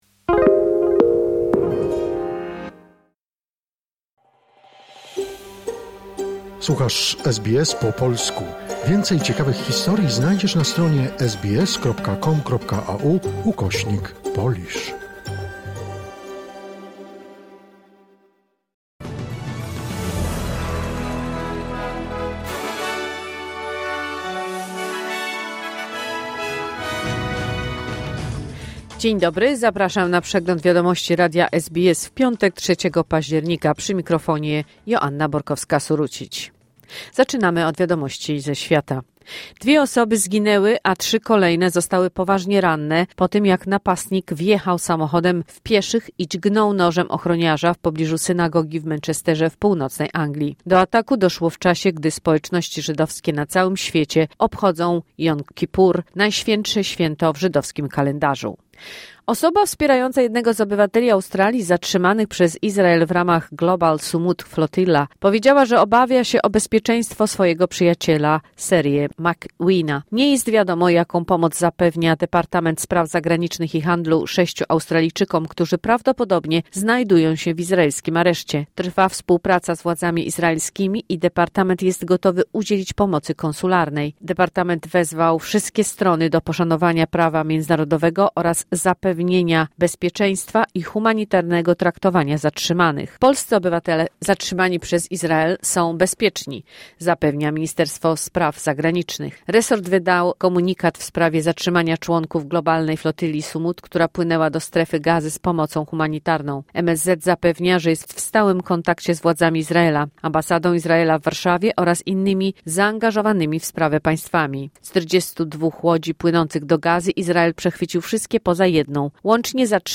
Wiadomości 3 października SBS News Flash